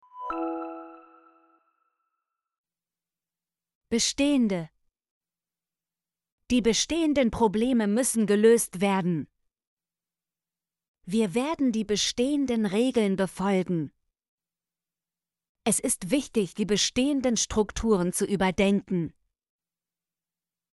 bestehende - Example Sentences & Pronunciation, German Frequency List